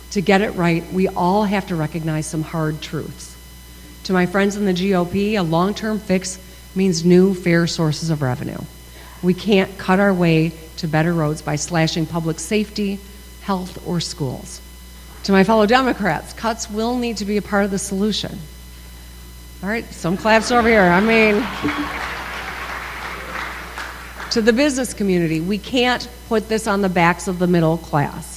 She delivered her 7th State of the State Address Wednesday night before a joint session of the Michigan House and Senate.